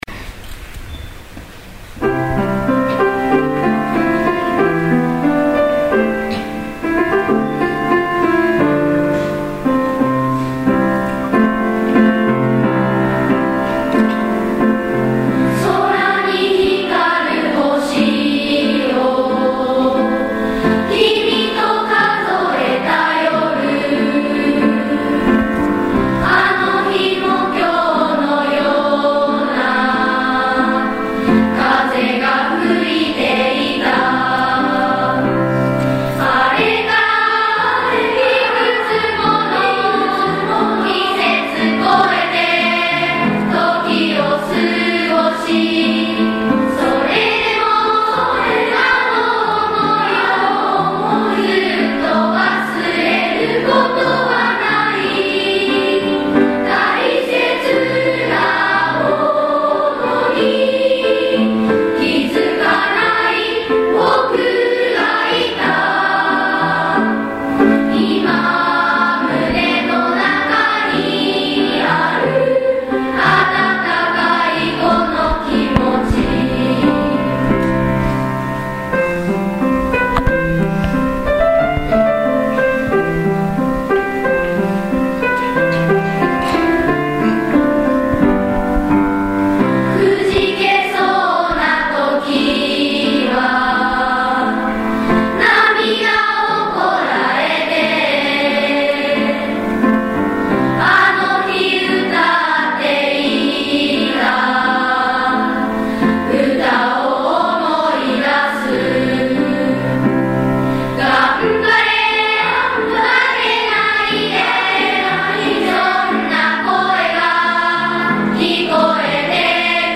５．６年生合同発表会
２月８日（水）に、高学年の合同発表会が行われました。
高学年の子どもたちの奏でる音色は、力強さと軽快さを織り交ぜた多彩な音色で、会場の聴衆も息を飲み、耳を傾けていました。